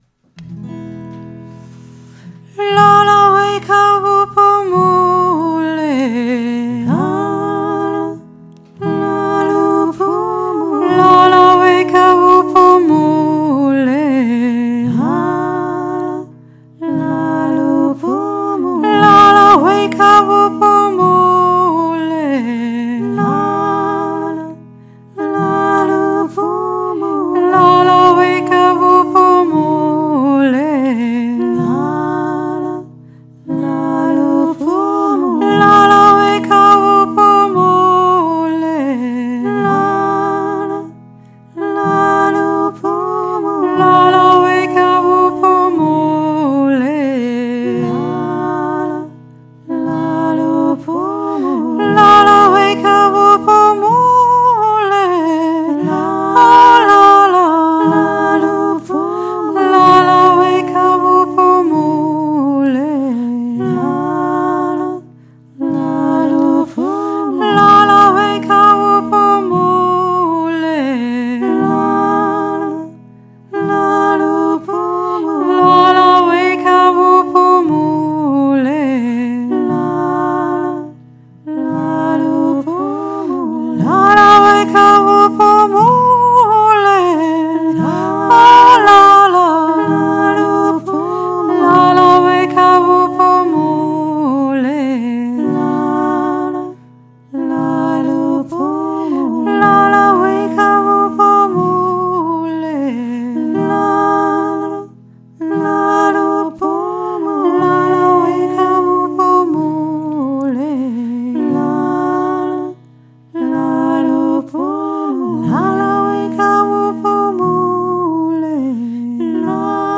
Goldegg jodelt und singt April 2023
Afrikanisches Schlaflied
la-la-afrikanisches-schlaflied.mp3